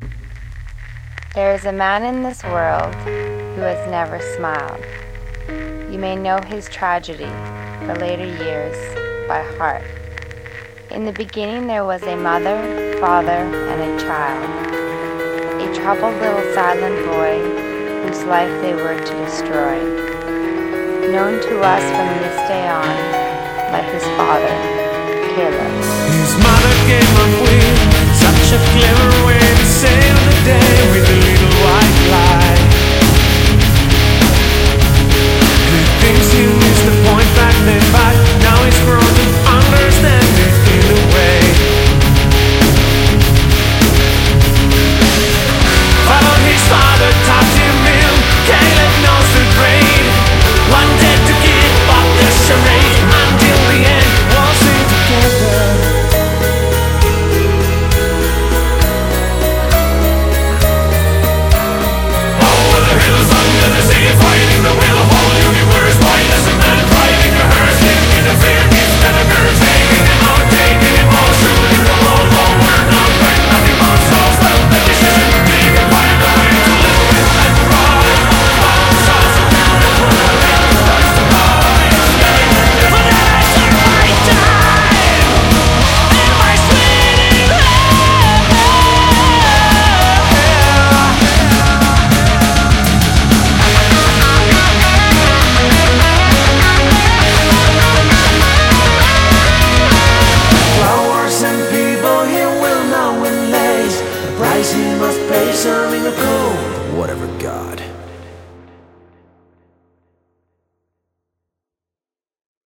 BPM96-200
Metal song, brackets, marked footswitches, sideswitches